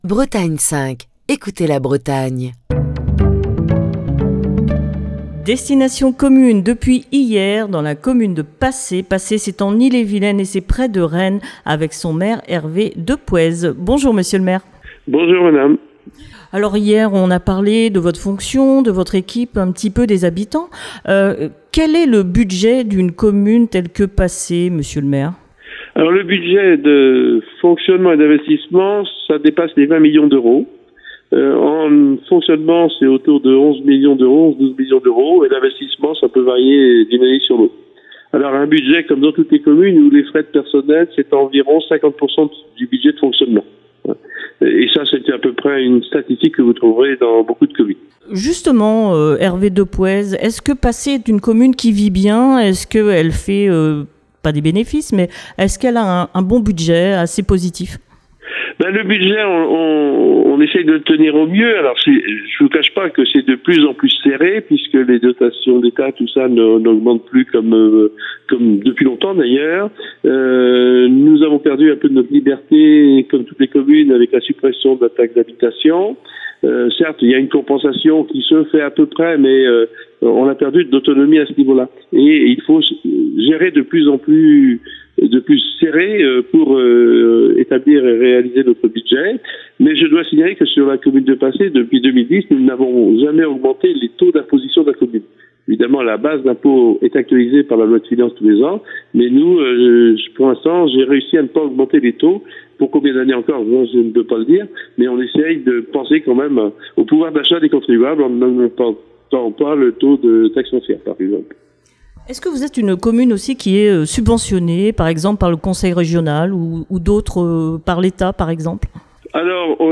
s'entretient au téléphone avec Hervé Depouez, maire de Pacé, qui détaille les projets actuels et futurs de la ville